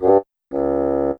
level_failed.wav